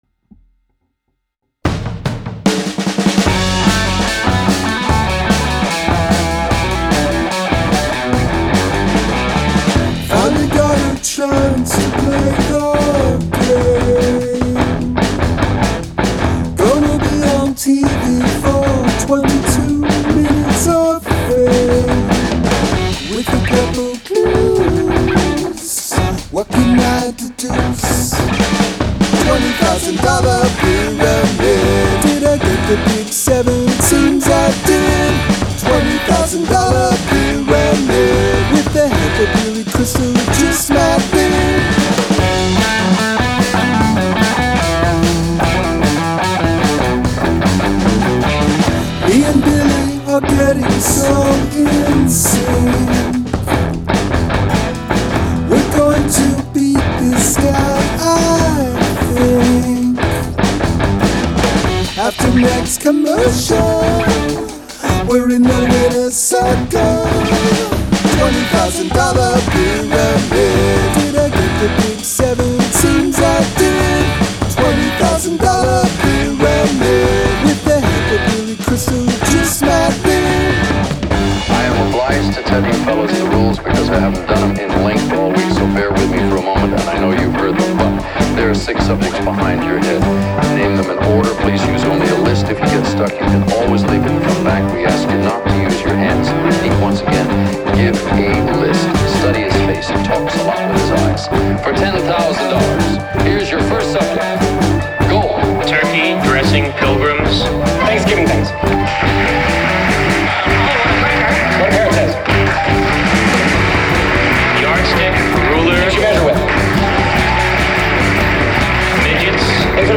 I have a 4 bar phrase as an intro.